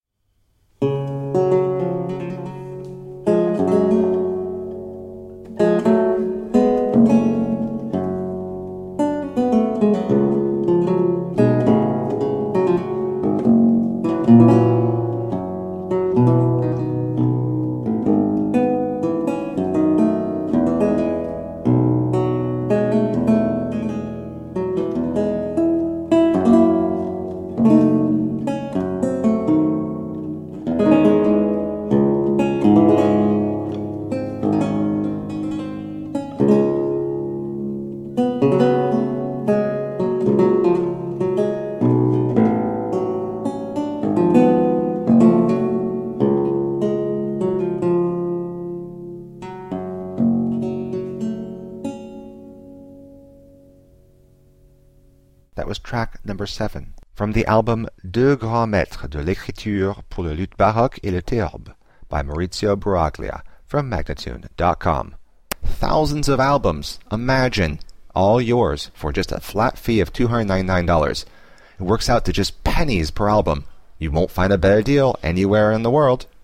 A marvelous classical spiral of lute sounds.
Classical, Baroque, Instrumental, Lute
Theorbo